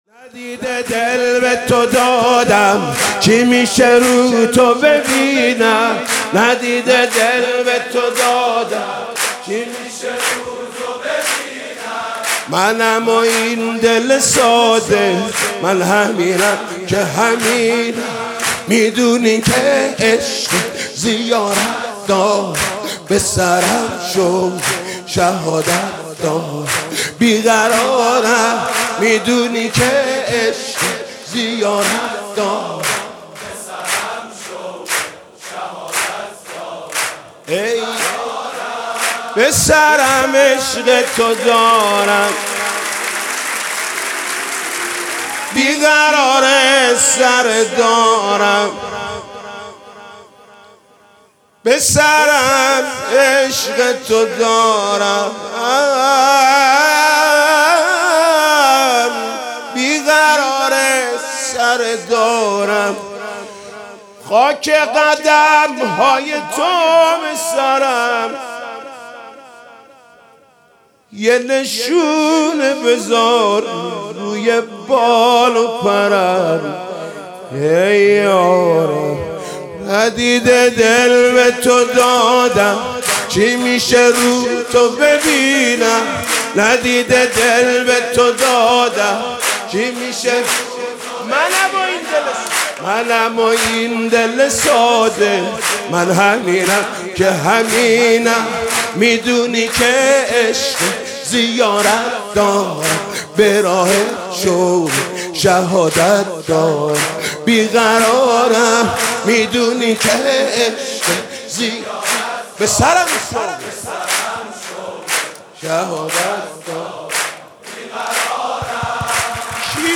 سرود: ندیده دل به تو دادم